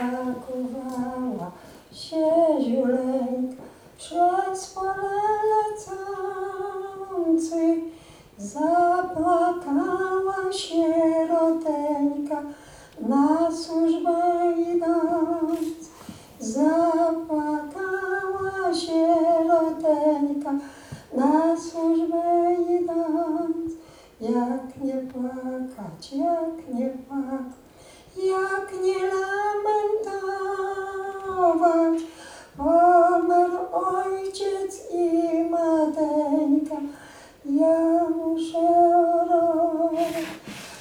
W niedzielę, 20 września, w Sali Kameralnej odbył się koncert tradycyjnych pieśni w wykonaniu zespołów ludowych, przy współudziale osób kultywujących ten gatunek muzyki.
Zabrzmiały zatem tradycyjne pieśni z naszego regionu – tęskne i radosne, mówiące o uczuciach, czasem szczęśliwych, czasem nietrafnie ulokowanych, o codziennym życiu, trudach pracy na roli, radościach i smutkach, ale też pięknie krajobrazu.